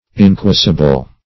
Inquisible \In*quis"i*ble\